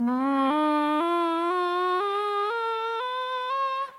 人类小号大音阶